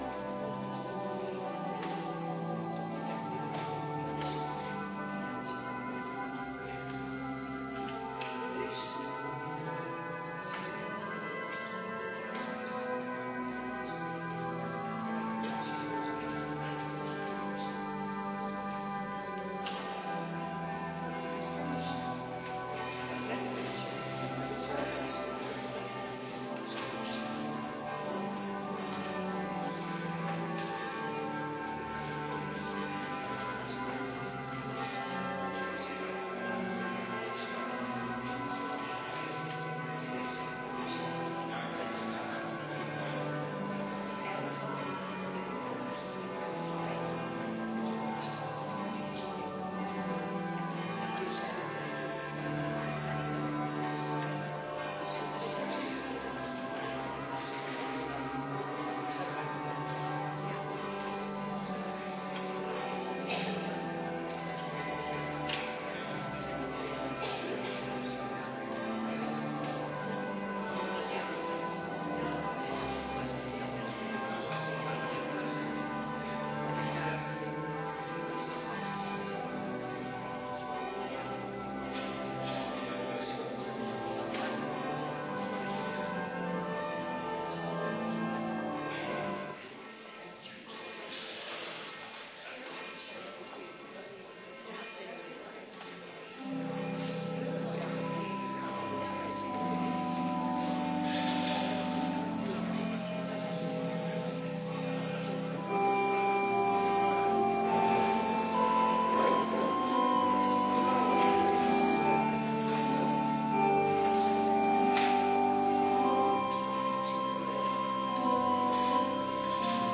Viering Heilig Avondmaal